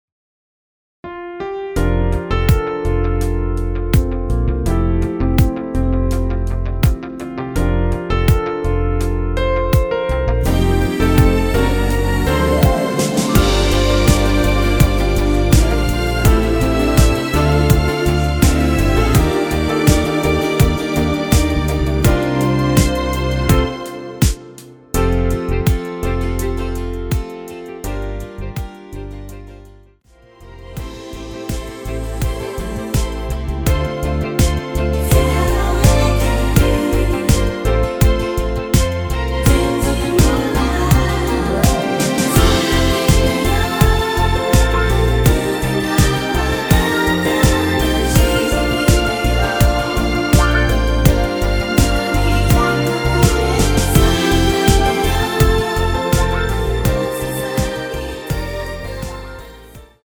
원키에서(+2)올린 코러스 포함된 MR입니다.
앞부분30초, 뒷부분30초씩 편집해서 올려 드리고 있습니다.